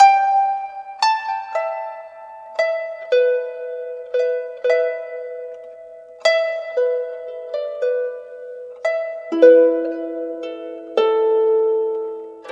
Harp02_115_G.wav